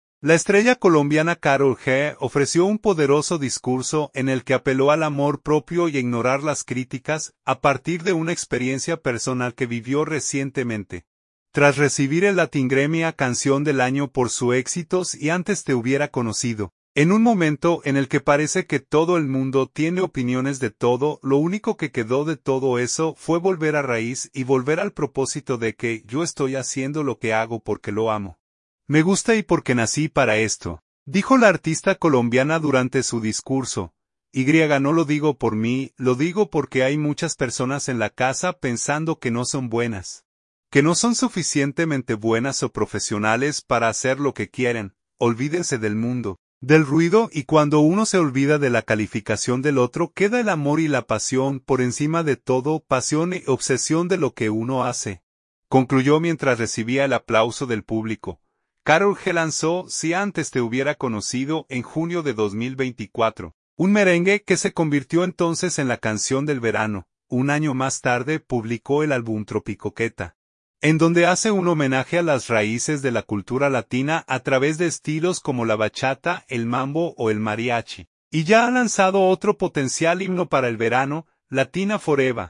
La estrella colombiana Karol G ofreció un poderoso discurso en el que apeló al amor propio y a ignorar las críticas, a partir de una experiencia personal que vivió recientemente, tras recibir el Latin Grammy a canción del año por su éxito ‘Si antes te hubiera conocido’.
Olvídense del mundo, del ruido y cuando uno se olvida de la calificación del otro queda el amor y la pasión, por encima de todo, pasión y obsesión de lo que uno hace”, concluyó mientras recibía el aplauso del público.